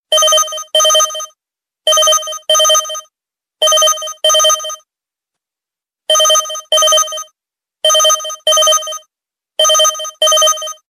Android, Klassisk Telefon, Klassisk